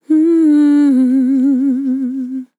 Categories: Vocals Tags: dry, english, female, fill, LOFI VIBES, MMM, sample
POLI-LYR-FILLS-120BPM-Am-1.wav